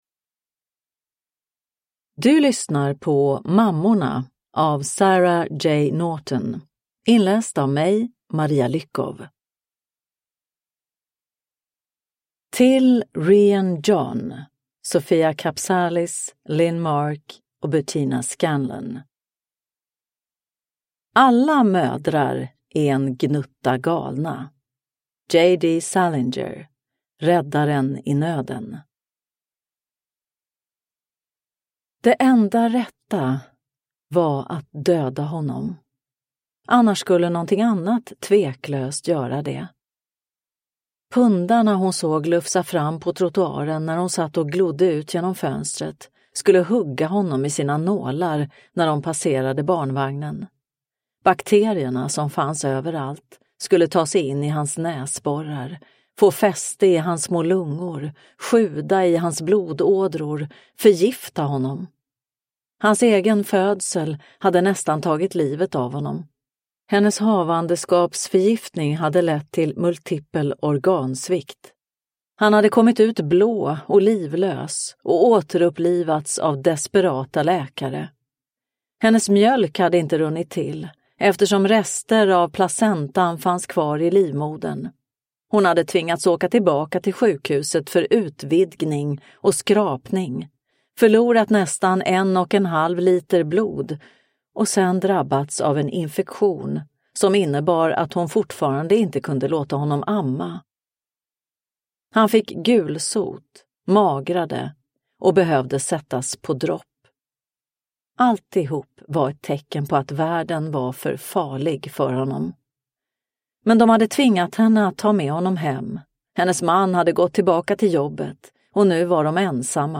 Mammorna – Ljudbok – Laddas ner